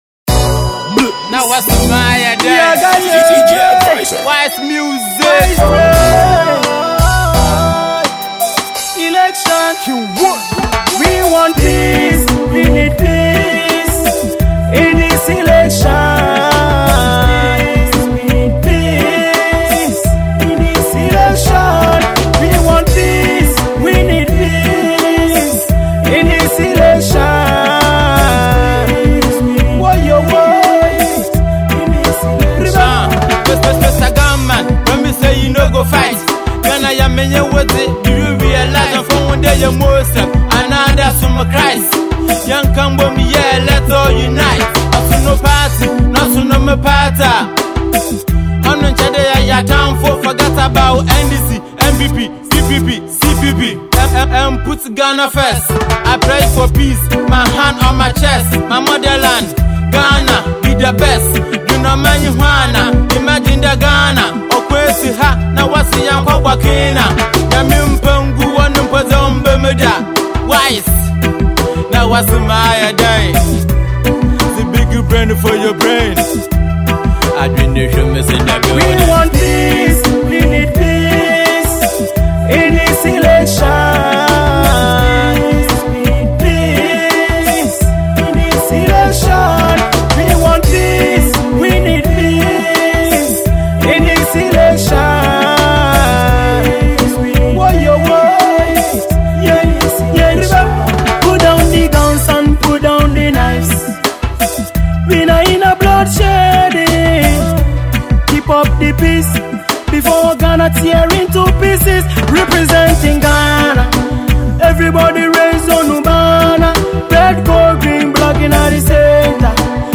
peaceful election song